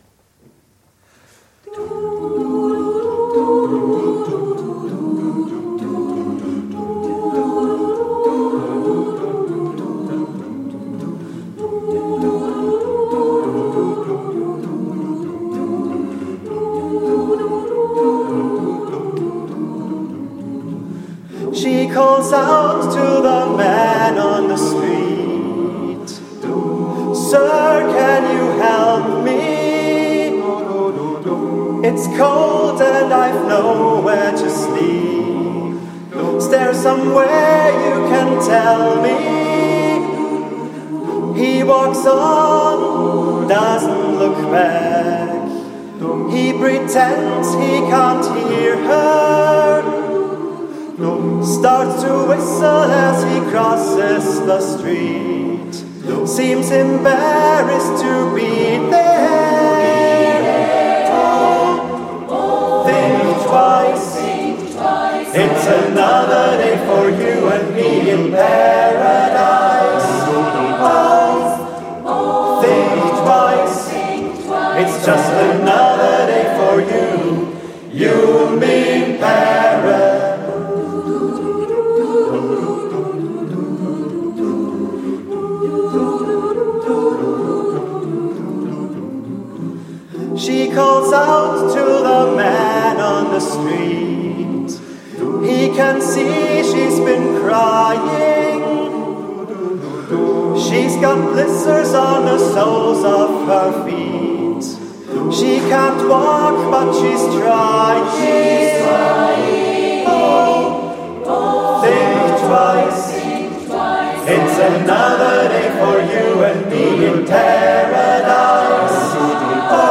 Ein gemischter Chor, der sein breites Repertoire mit viel Freude zum Besten bringt.
Mitschnitt vom ORF-Auftritt im November 2019